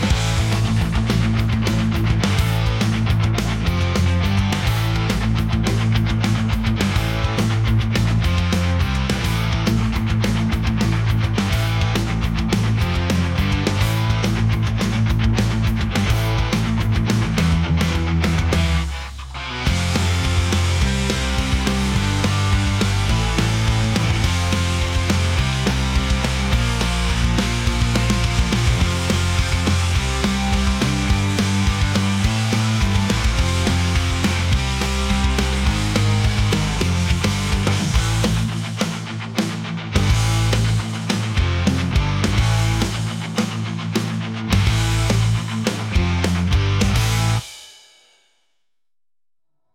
punk | rock | aggressive